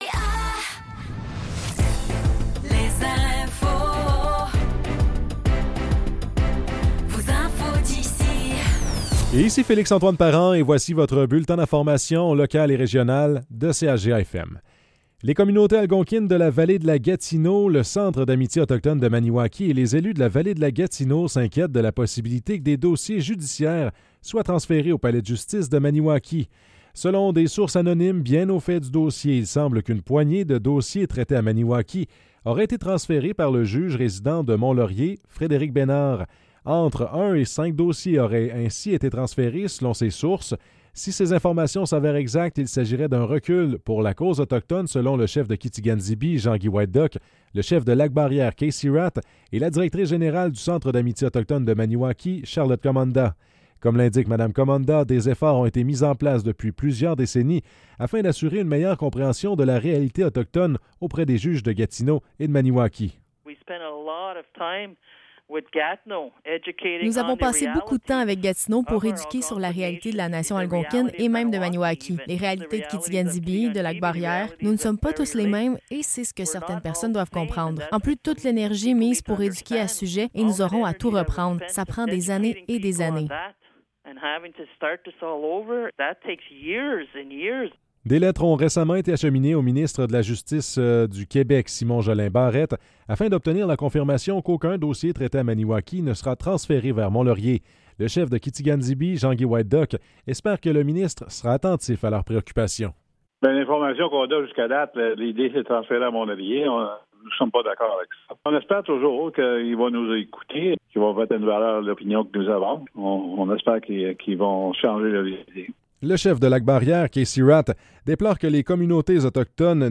Nouvelles locales - 24 octobre 2024 - 15 h